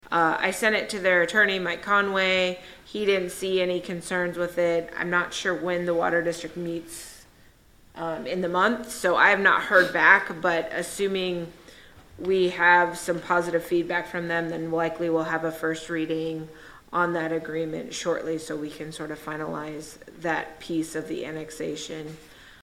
The Boonville City Council received a report from City Administrator Kate Fjell about a water district buyout agreement during its meeting on May 16.